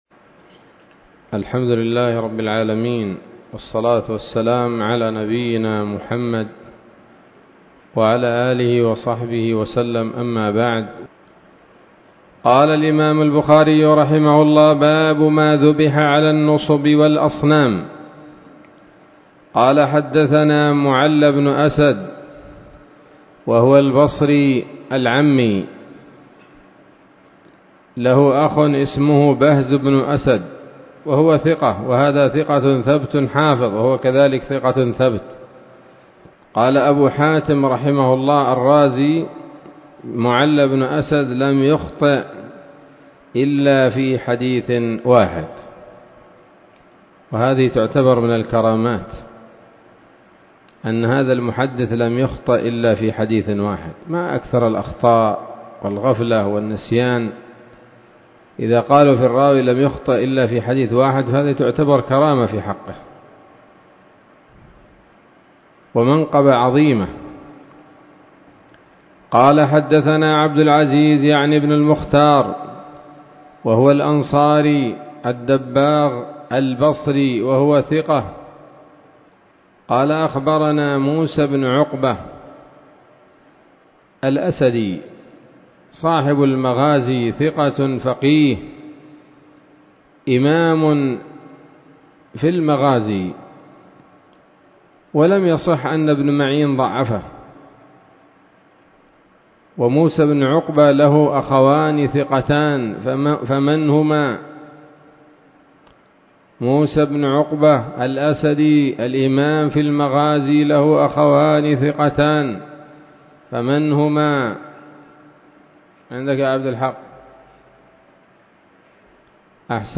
الدرس الرابع عشر من كتاب الذبائح والصيد من صحيح الإمام البخاري